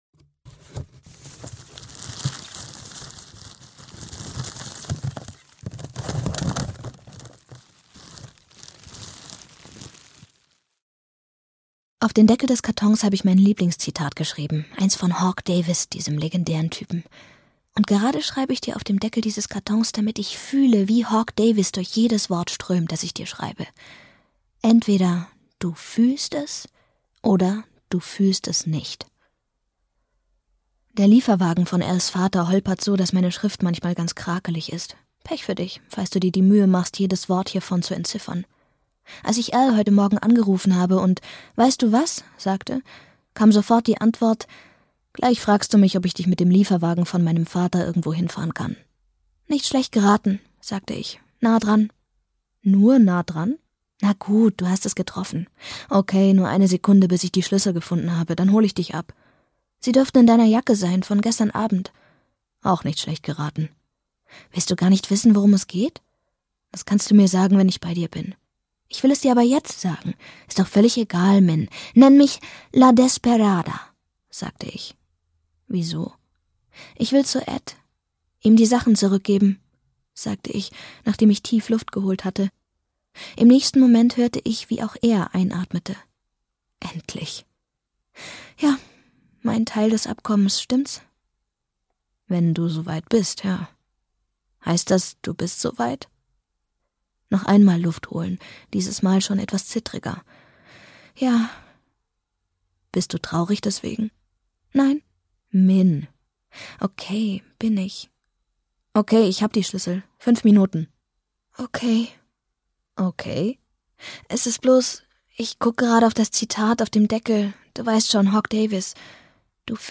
Hörbuch 43 Gründe, warum es AUS ist, Daniel Handler.